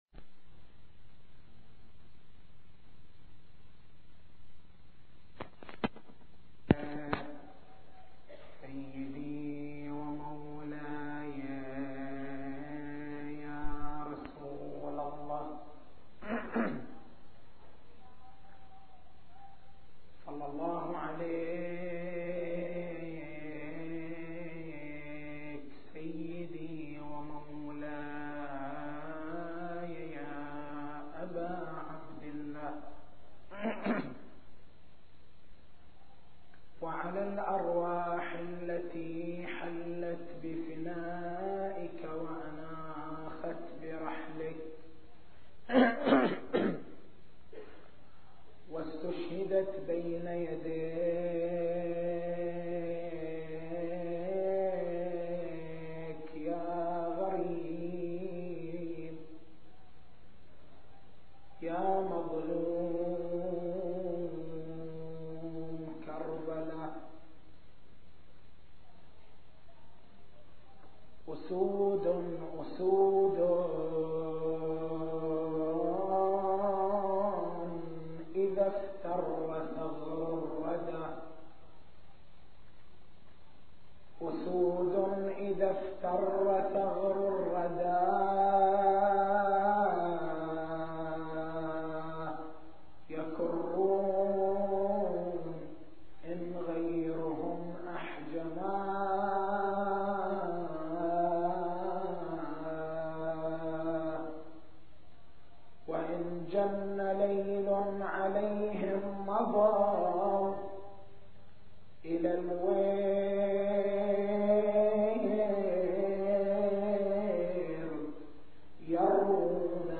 تاريخ المحاضرة: 06/01/1424 نقاط البحث: مكانة العقل عند الشيعة دور العقل في القضايا العقائدية دور العقل في الأحكام الشرعية التسجيل الصوتي: تحميل التسجيل الصوتي: شبكة الضياء > مكتبة المحاضرات > محرم الحرام > محرم الحرام 1424